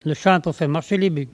Il chante pour faire avancer les boeufs
Catégorie Locution